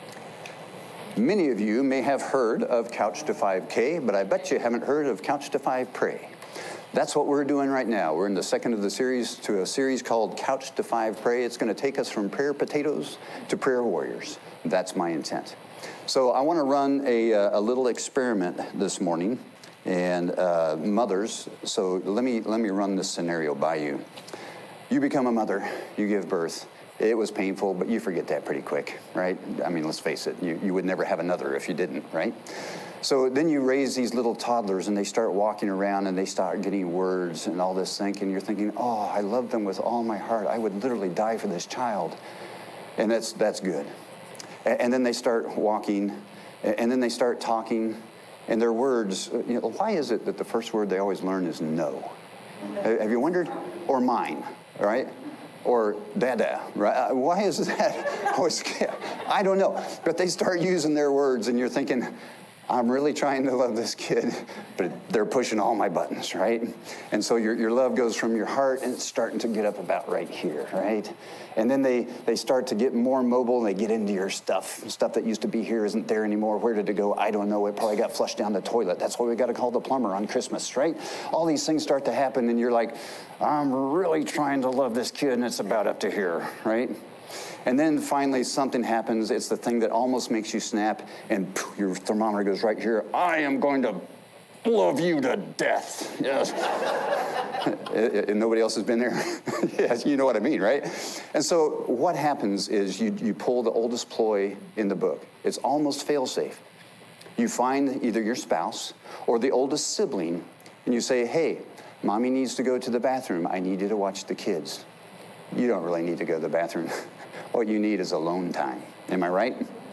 Sermons
audio-sermon-shut-the-front-door.m4a